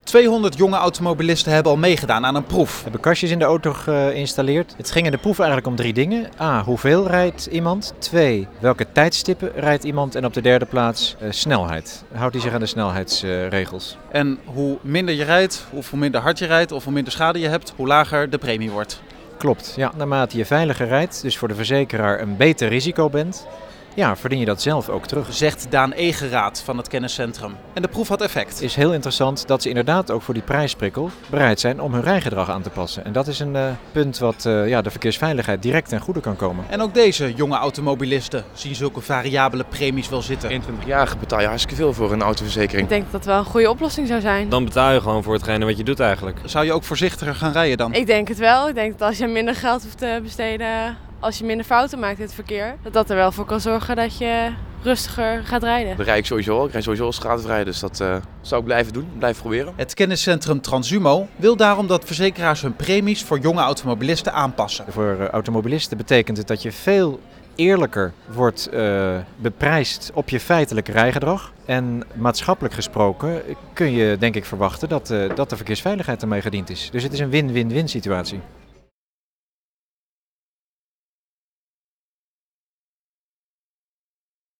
Interview 3FM Kilometerverzekeren